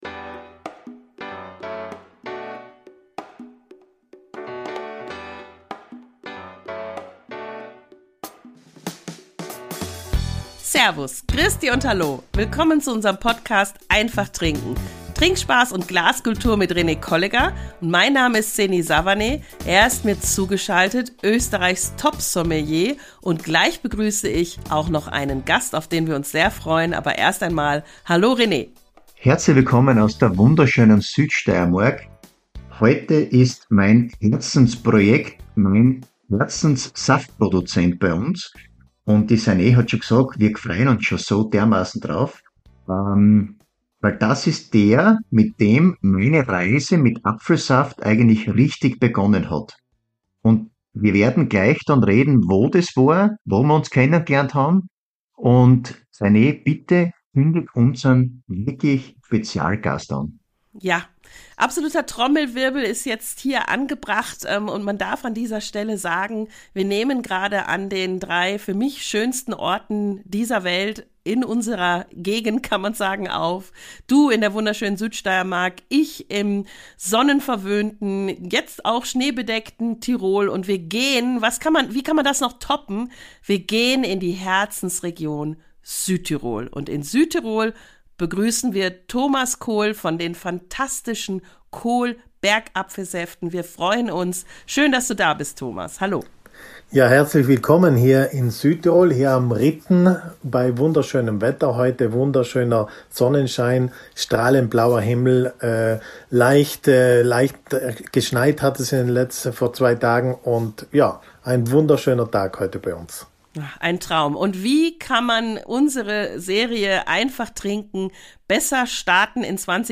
Beschreibung vor 2 Monaten Die erste Folge von EINFACH TRINKEN in diesem Jahr startet mit einem Winzer-Talk!